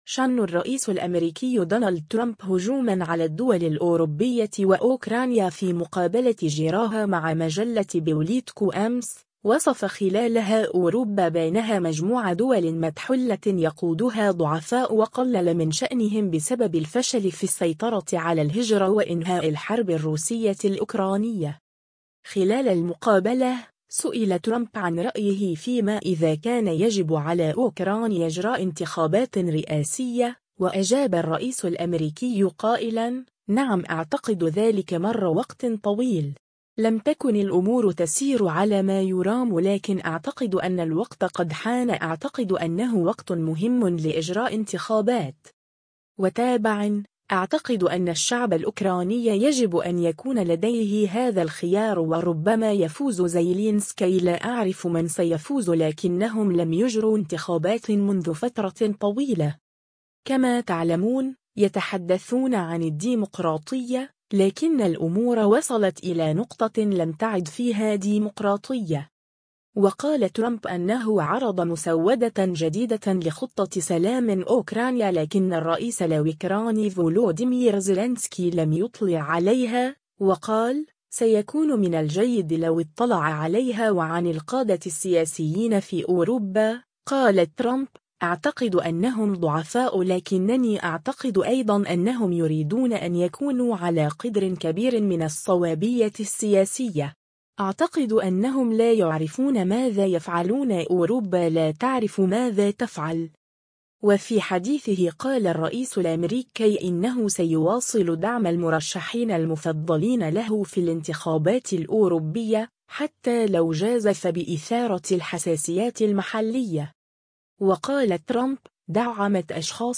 شن الرئيس الأمريكي دونالد ترامب هجوما على الدول الأوروبية و أوكرانيا في مقابلة اجراها مع مجلة بوليتكو أمس، وصف خلالها أوروبا بانها مجموعة دول متحللة يقودها ضعفاء و قلّل من شأنهم بسبب الفشل في السيطرة على الهجرة و إنهاء الحرب الروسية الأوكرانية.